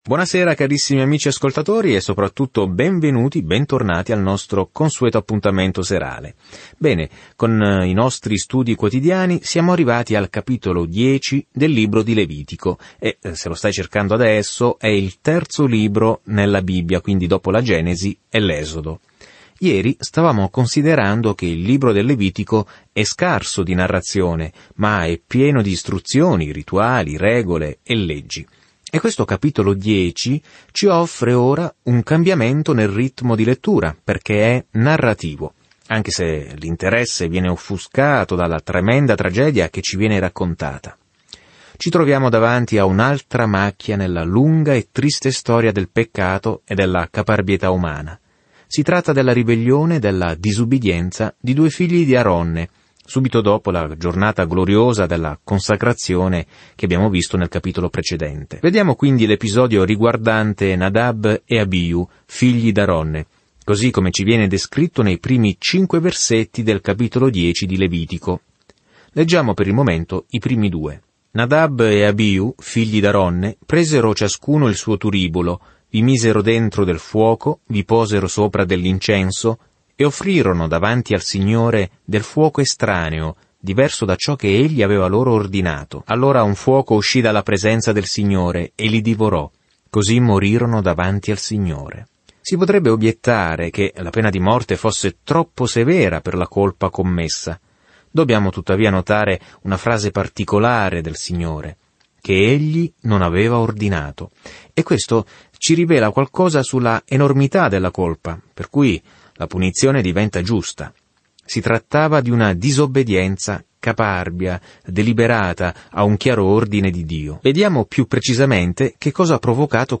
Viaggia ogni giorno attraverso il Levitico mentre ascolti lo studio audio e leggi versetti selezionati della parola di Dio.